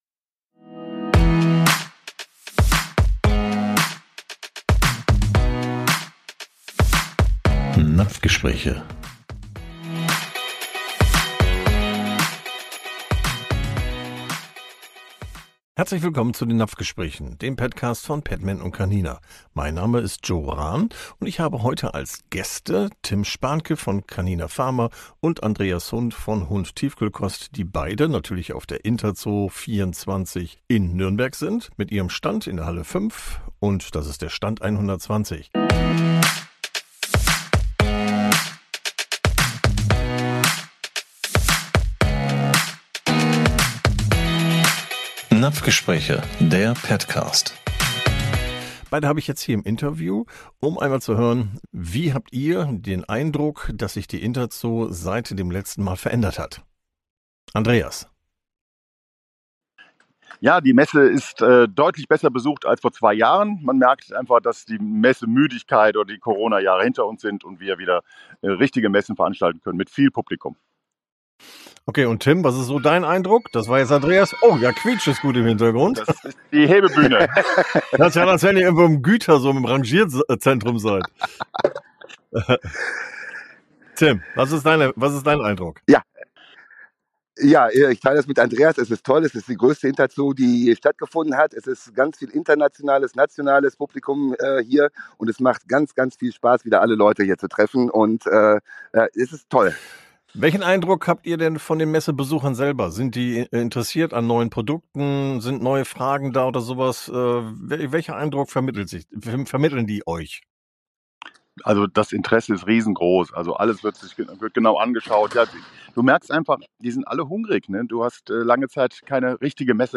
Weltleitmesse für die internationale Heimtierbranche
in Nürnberg vom 07 bis 10 Mai 2024. Sie berichten live vor Ort von